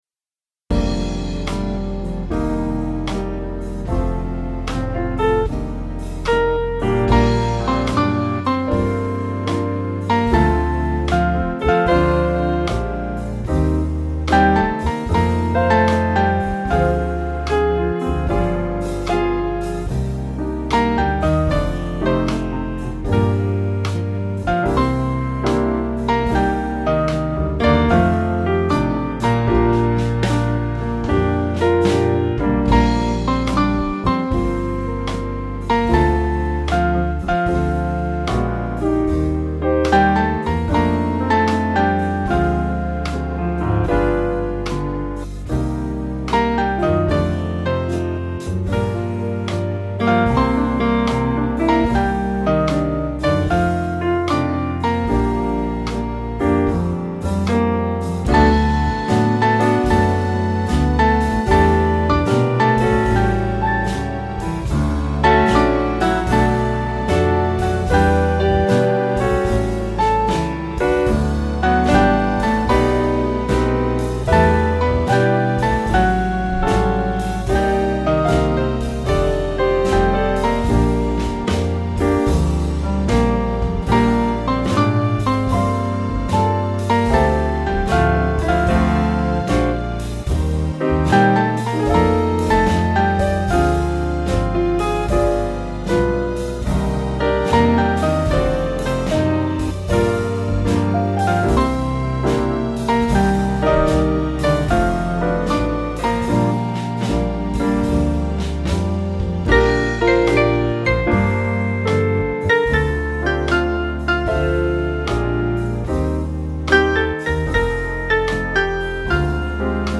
Small Band
(CM)   Bb